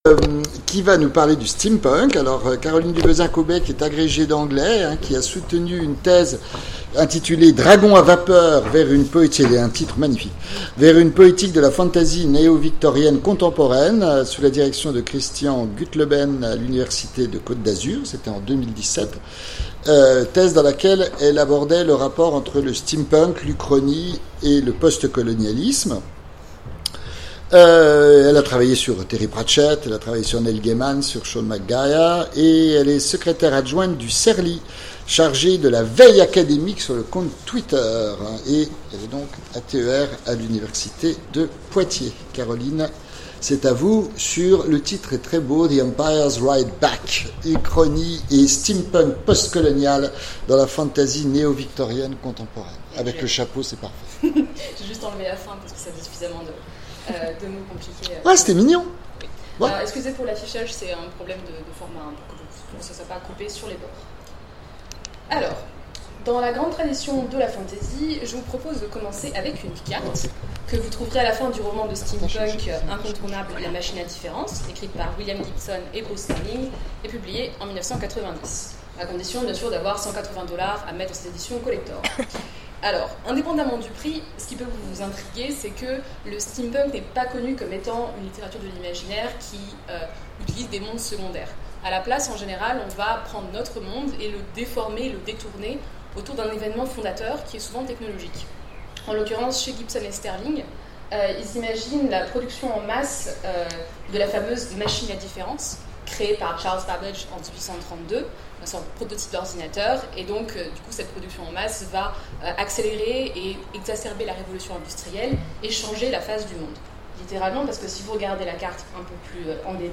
Colloque universitaire 2018 : The Empire Writes Back
Mots-clés Steampunk Conférence Partager cet article